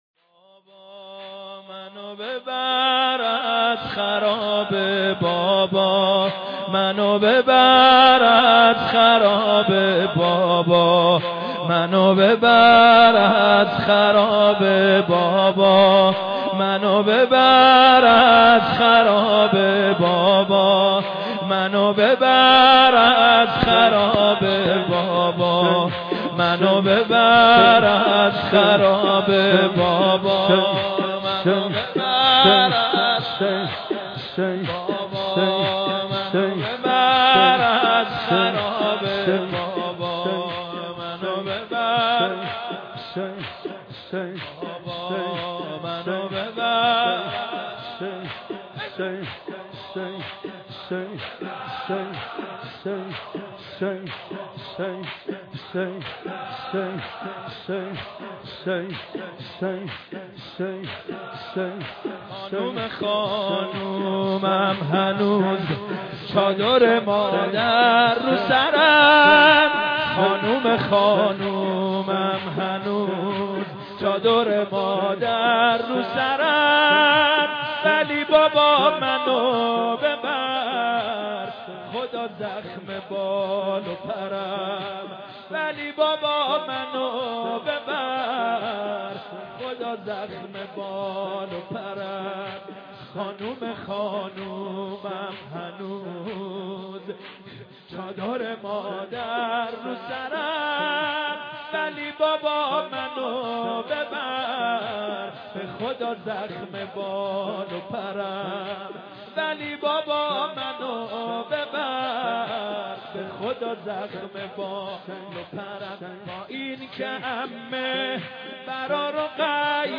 متن مداحی شب سوم محرم به سبک زمینه